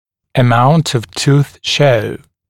[ə’maunt əv tuːθ ʃəu][э’маунт ов ту:с шоу]степень экспозиции зубов, степень обнажения зубов